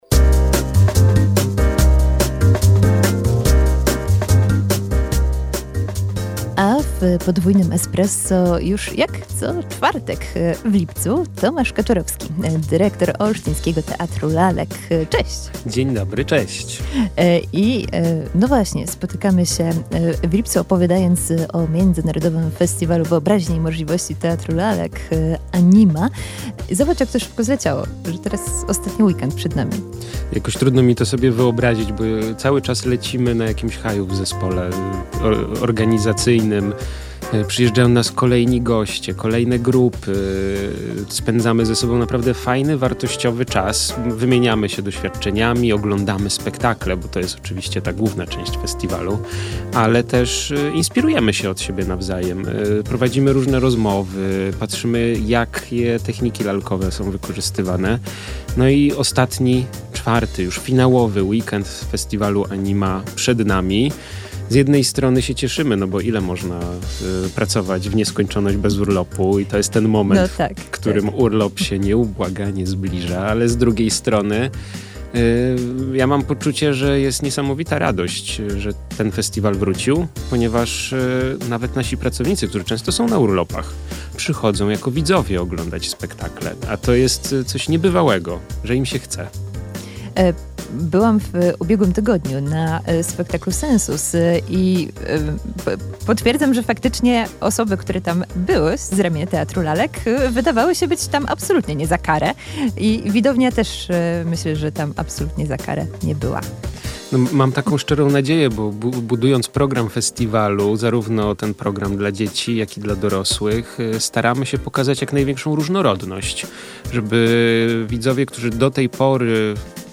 w radiowym studiu